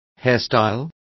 Complete with pronunciation of the translation of hairstyle.